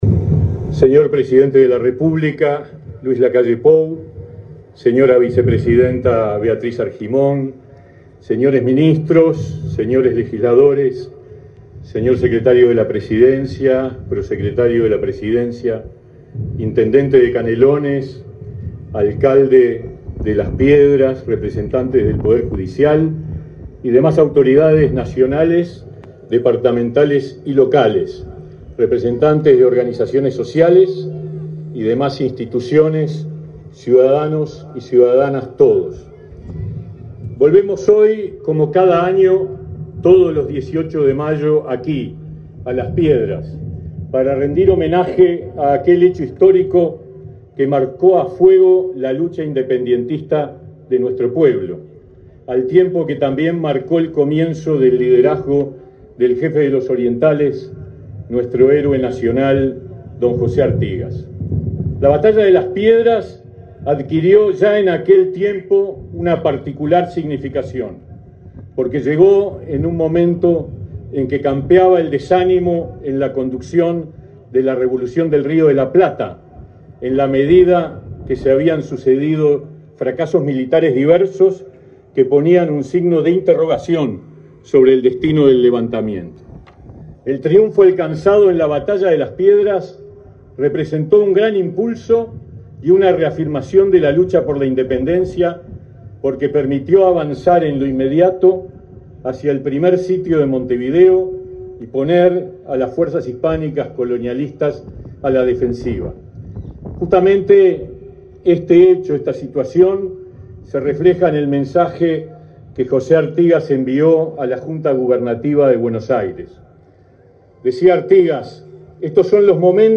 Palabras del ministro de Trabajo y Seguridad Social, Pablo Mieres
Palabras del ministro de Trabajo y Seguridad Social, Pablo Mieres 18/05/2023 Compartir Facebook X Copiar enlace WhatsApp LinkedIn El presidente de la República, Luis Lacalle Pou, participó, este 18 de mayo, en el acto por el 212.° aniversario de la Batalla de Las Piedras, en esa localidad del departamento de Canelones. En la oportunidad, el ministro de Trabajo y Seguridad Social, Pablo Mieres, realizó declaraciones.